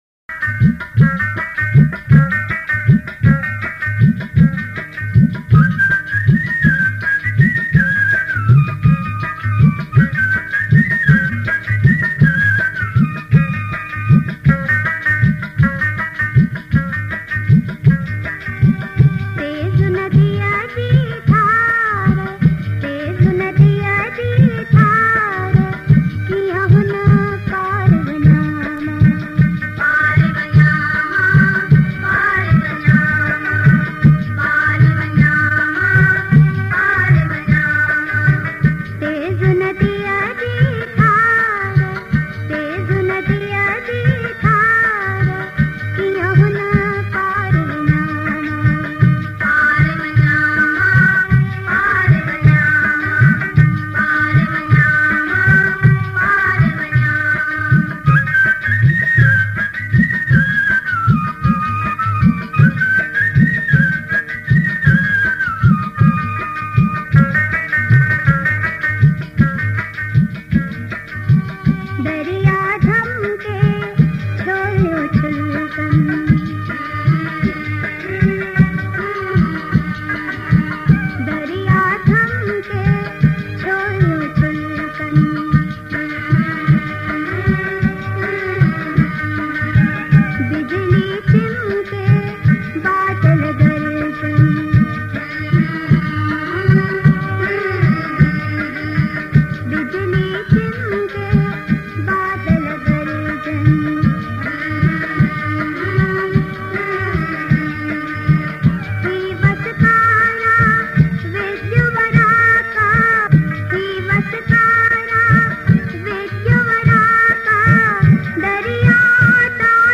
Sindhi Kalam, Geet, Qawali, Duet